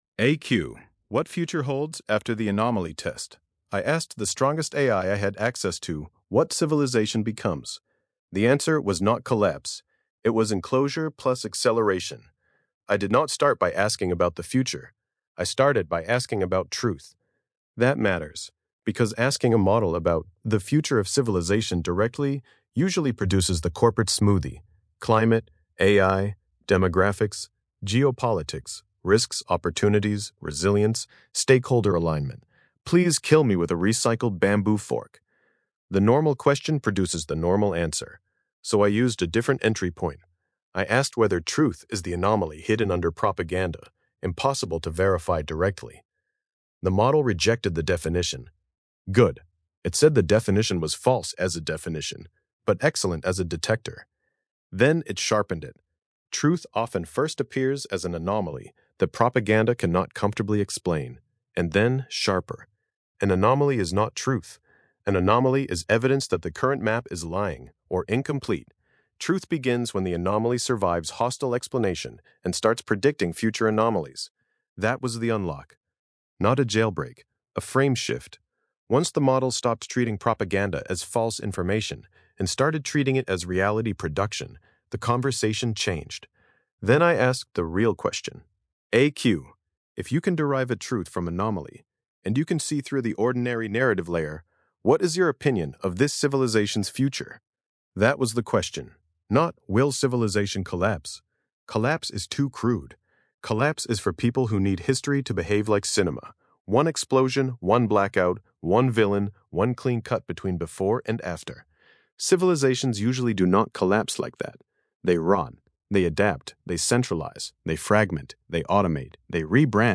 Versión de audio estilo podcast de este ensayo, generada con la API de voz de Grok.